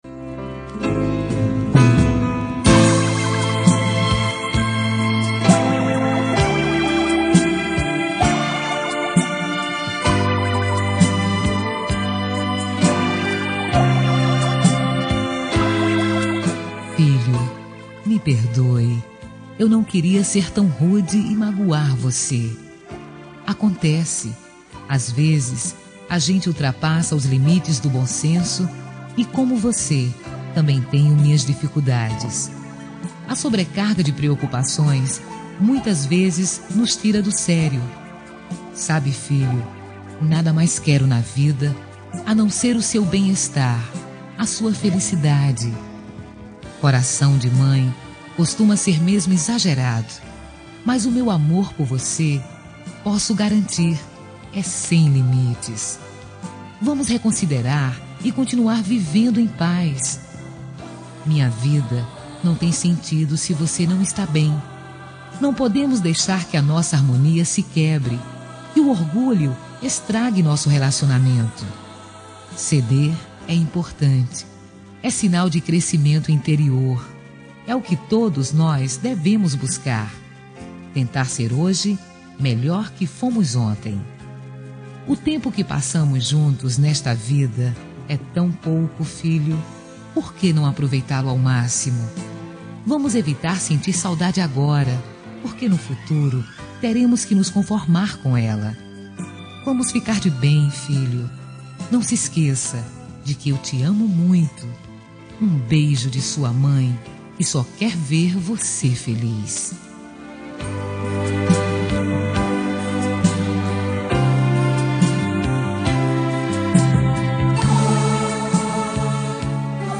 Reconciliação Familiar – Voz Feminina – Cód: 088727 – Filho